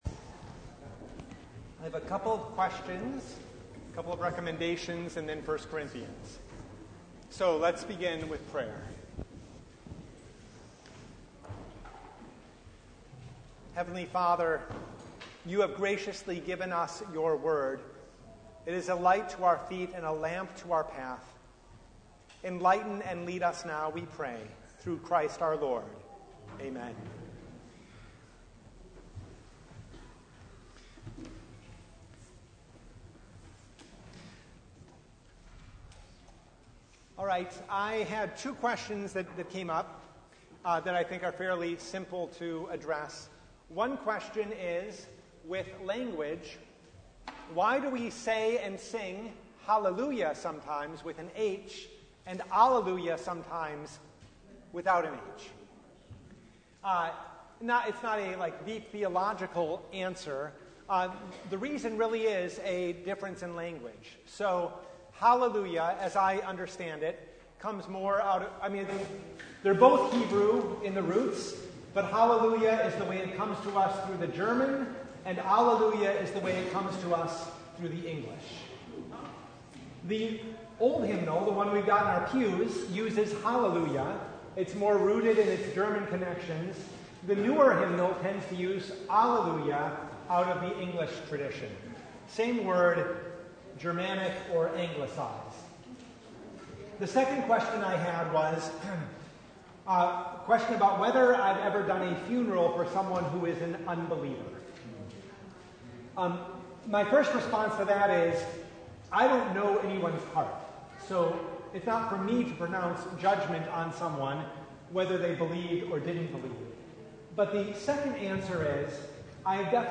1 Corinthians 3:18-4:8 Service Type: Bible Hour Topics: Bible Study